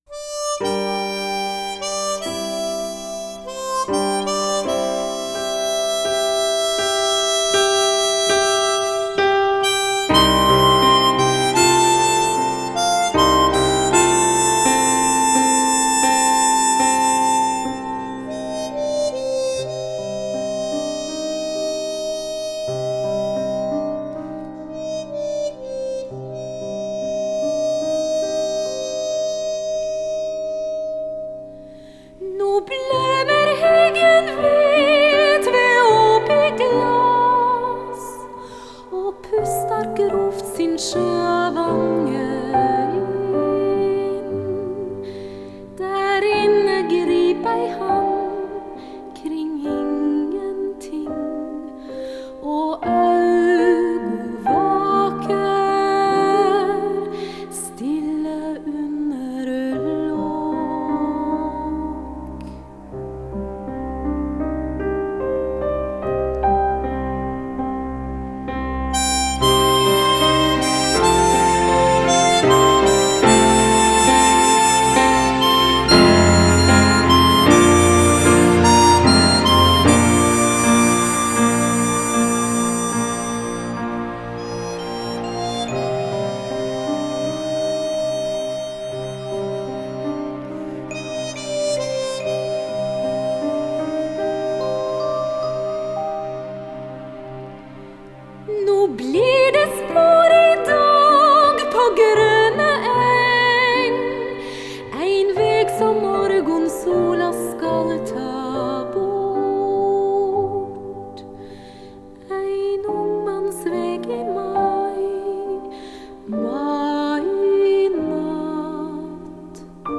口琴演奏